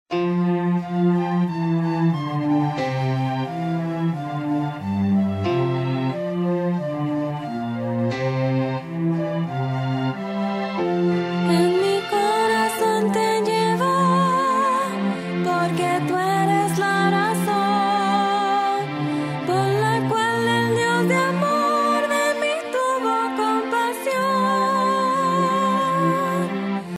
primer CD coral